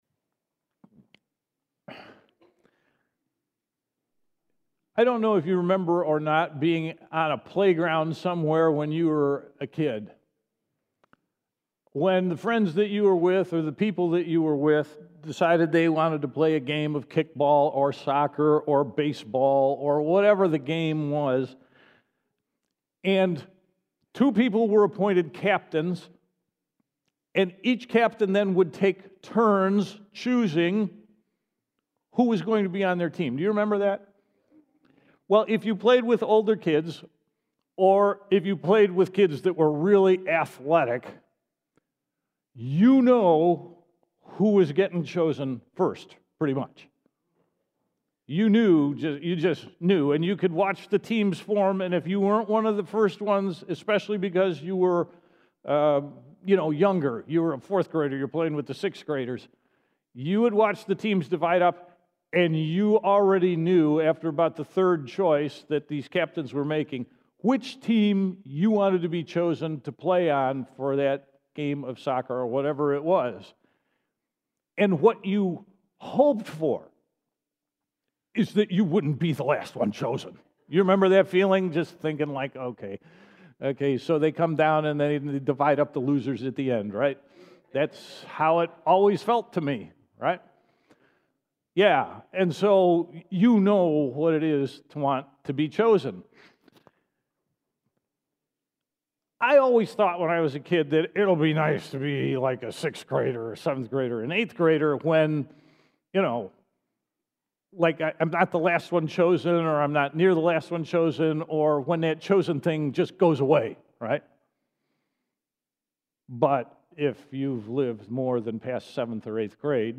Sermons | Syracuse Alliance Church